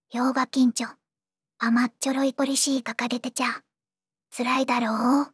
voicevox-voice-corpus / ROHAN-corpus /ずんだもん_ヒソヒソ /ROHAN4600_0011.wav